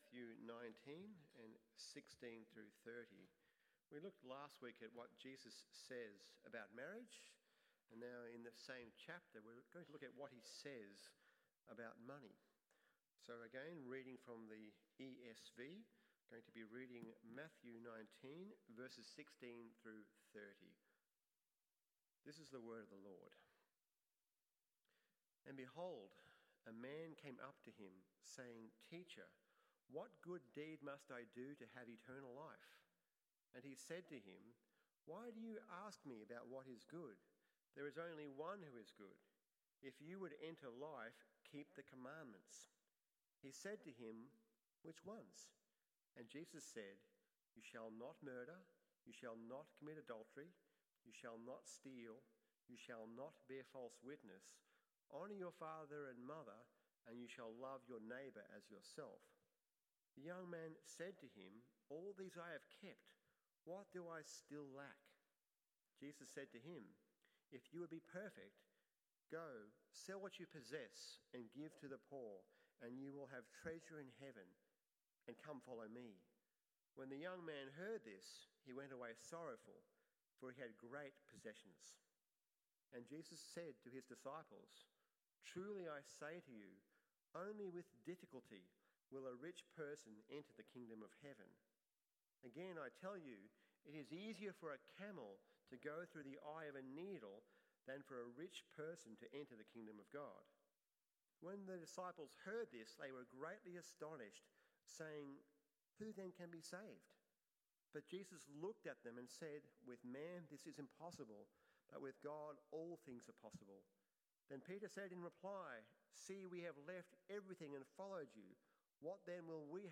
What Does Jesus Say About Money? PM Service